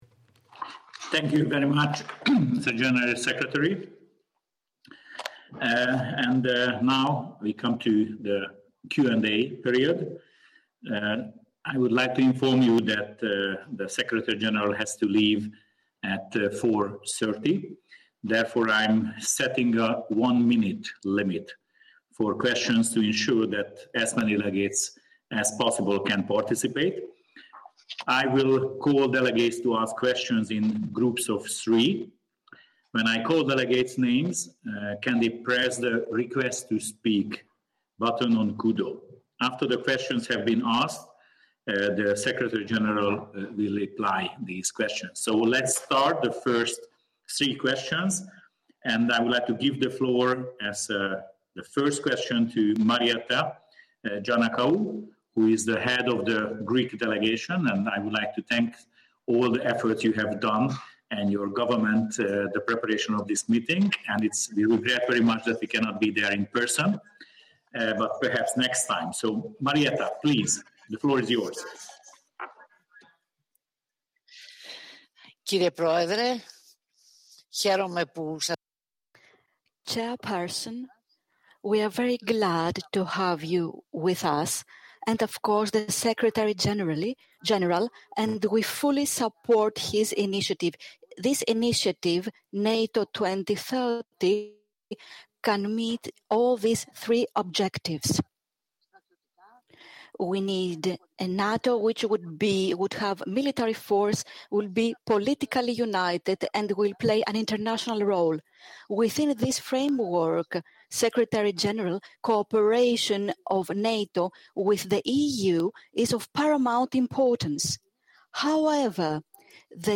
Secretary General addresses the NATO Parliamentary Assembly – adapting NATO for 2030 and beyond